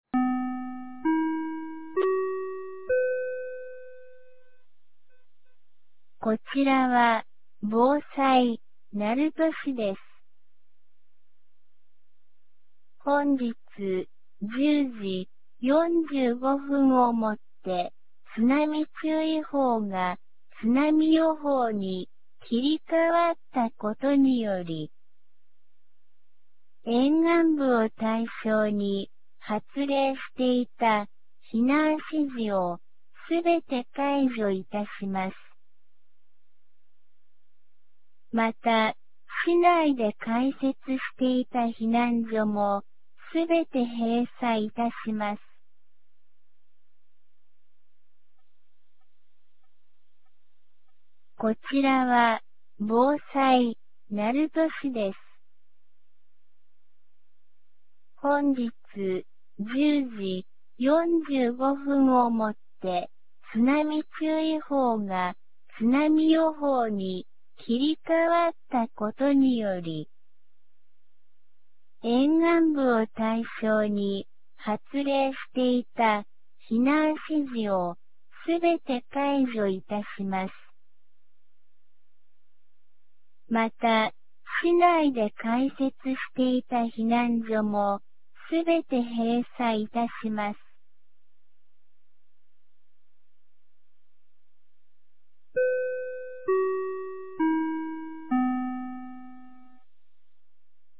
2025年07月31日 12時01分に、鳴門市より全地区へ放送がありました。